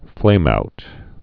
(flāmout)